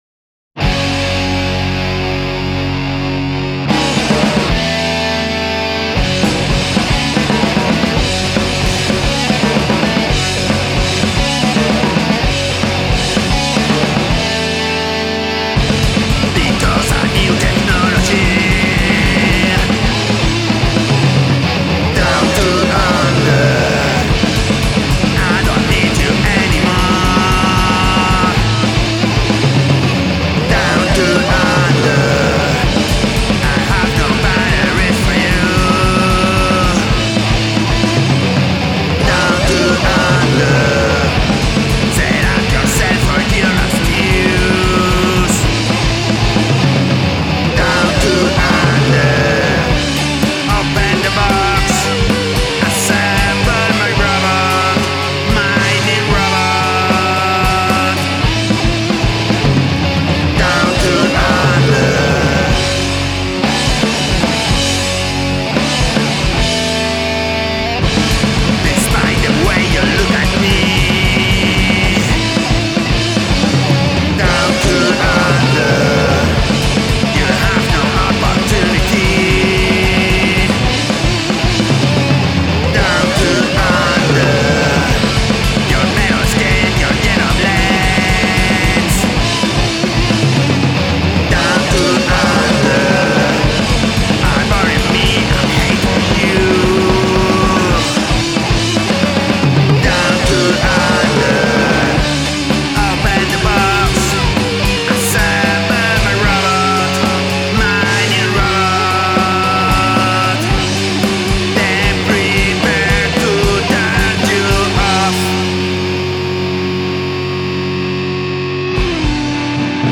Metal
Your singing reminds me of Tuvan throat singers.